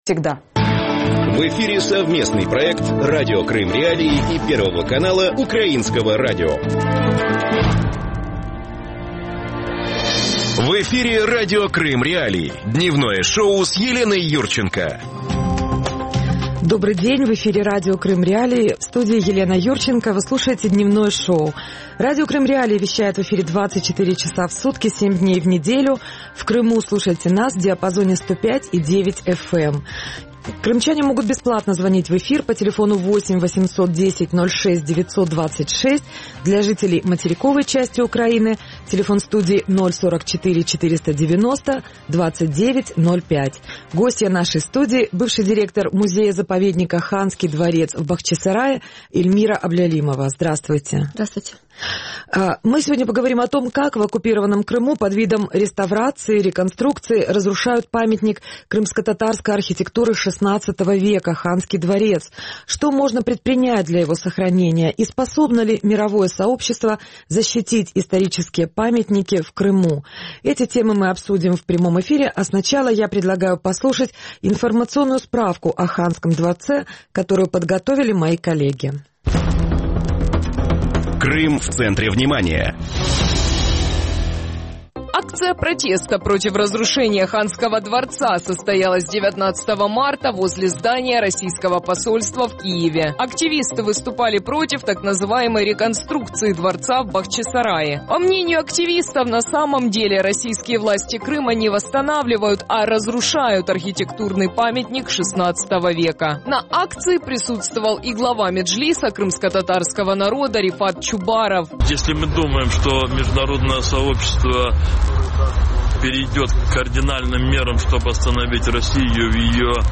Как в аннексированном Крыму под видом «реконструкции» разрушают памятник крымскотатарской архитектуры – Ханский дворец? Что можно предпринять для его сохранения? Способно ли мировое сообщество защитить исторические памятники в Крыму? Об этом – в Дневном шоу в эфире Радио Крым.Реалии с 12:10 до 12:40 (с 13:10 до 13:40 в Крыму).